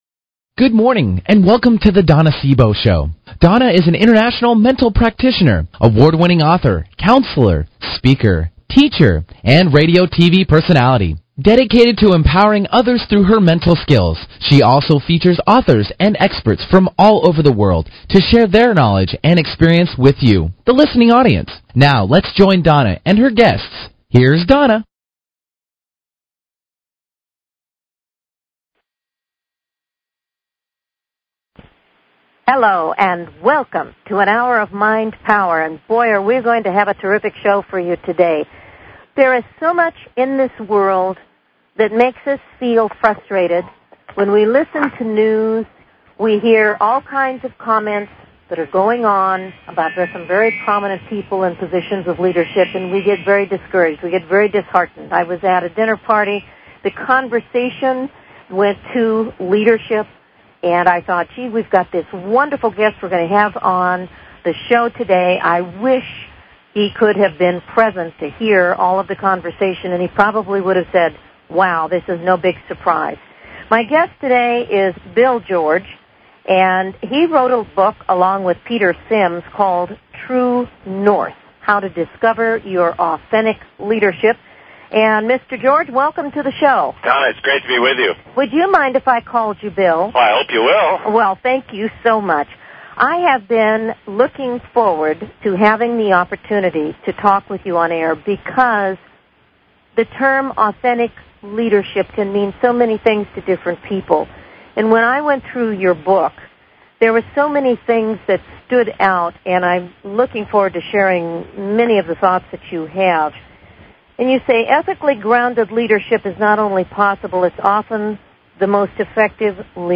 Talk Show Episode
'True North', Bill George. This is a rescheduled interview as Mr. George had to leave for Saudi Arabia the day of our scheduled interview on June 7th. This is a man with a passionate purpose for sharing positive directions for powerful leadership is a special way.